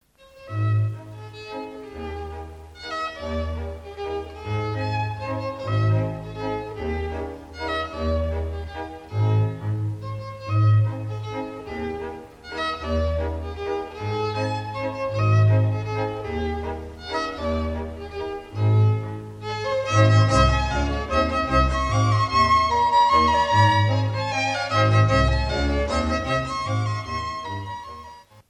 Ländler = Sammelbegriff für eine Melodie im langsamen Dreivierteltakt.